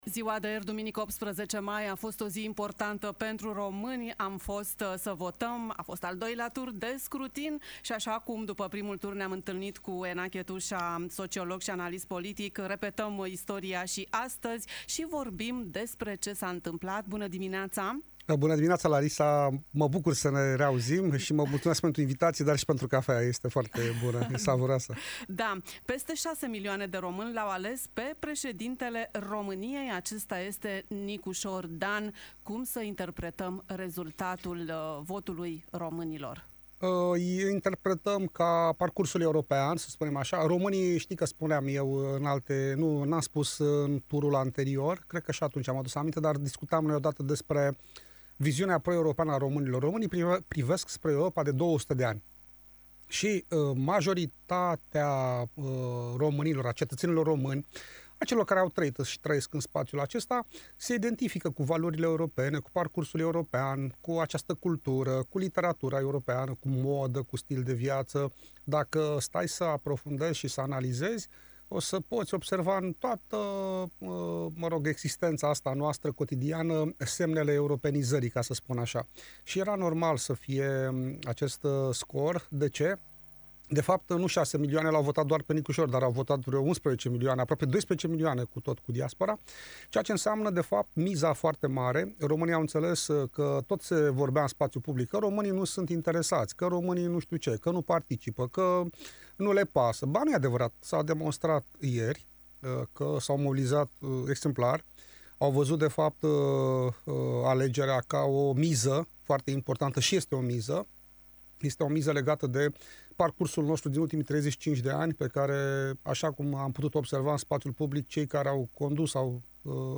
Ce înseamnă rezultatele turului al doilea al alegerilor prezidențiale pentru România. Interviu cu sociologul și analistul politic